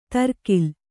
♪ tarkil